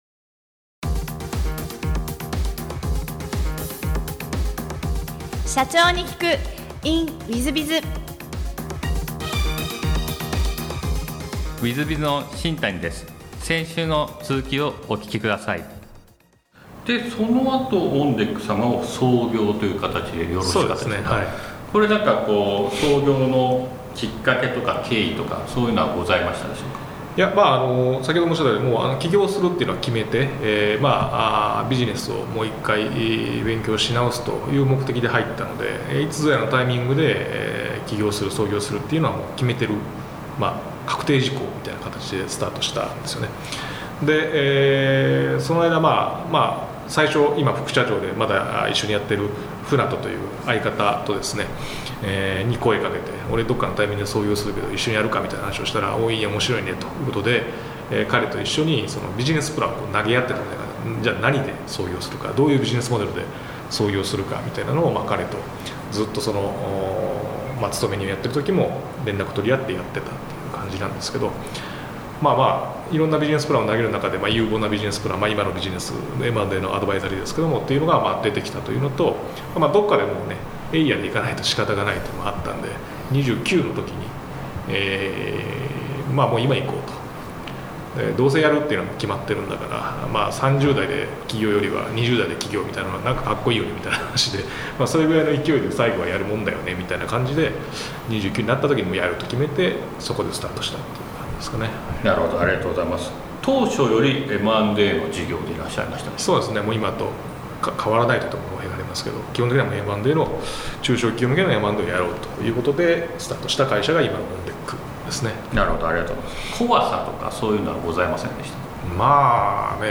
M&A事業で業績を上げ、上場へと導いたエピソードから経営のヒントが得られます。ぜひ、インタビューをお聞きください。